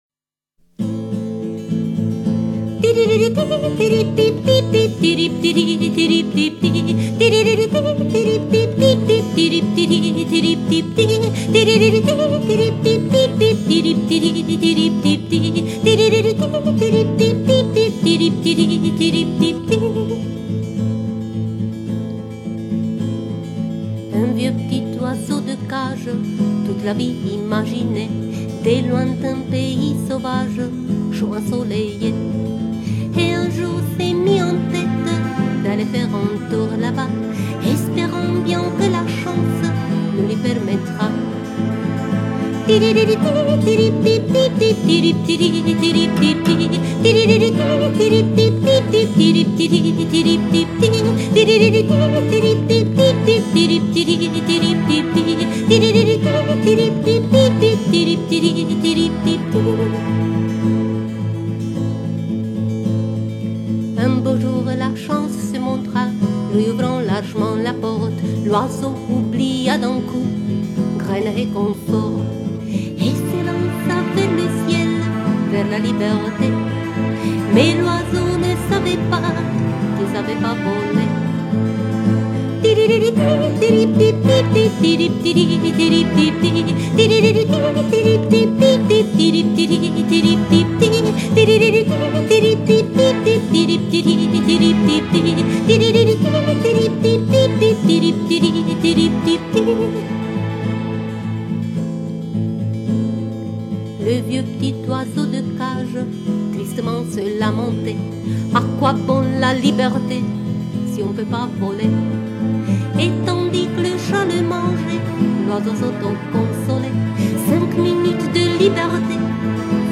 musique, paroles, voix et guitare